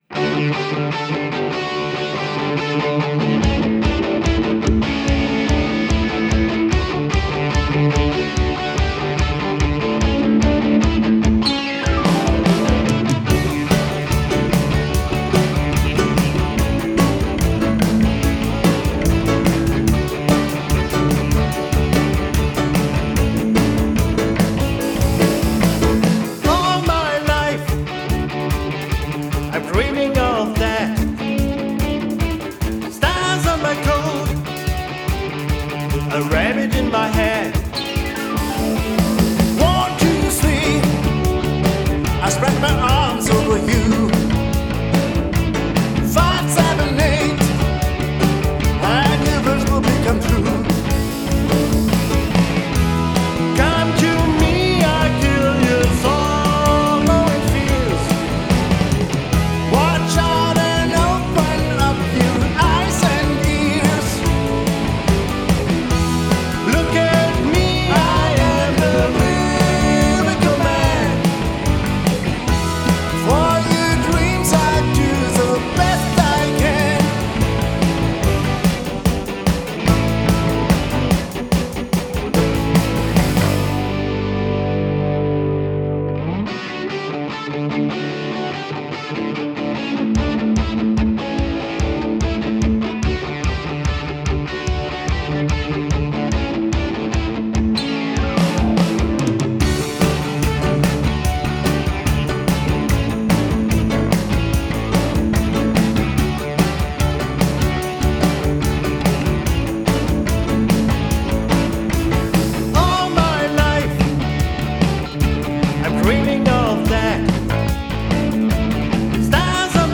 Zumindest am Anfang lädt dieses Stück zum Tanzen ein!
ist einfach ein  fetziges und fröhliches Stück!